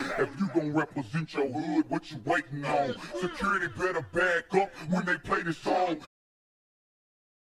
security back up.wav